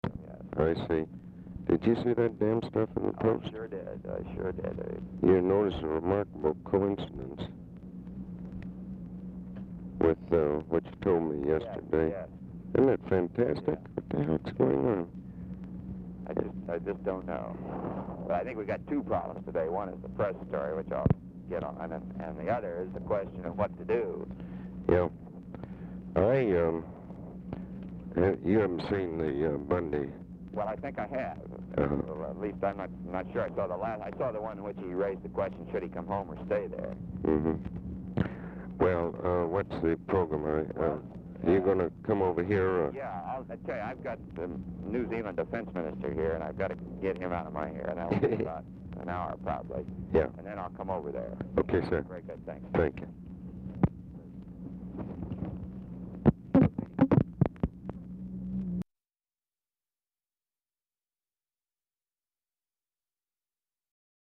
Telephone conversation # 7941, sound recording, ABE FORTAS and ROBERT MCNAMARA, 5/20/1965, time unknown | Discover LBJ
POOR SOUND QUALITY
Format Dictation belt
Location Of Speaker 1 White House Situation Room, Washington, DC